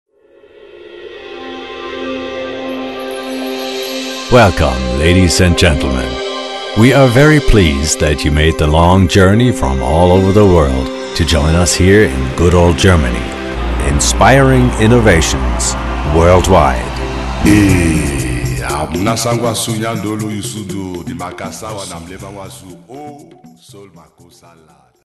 Stimme: Weich, warm, werblich - authoritär. Die sonore Stimme eines Erzählers
Kein Dialekt
Sprechprobe: eLearning (Muttersprache):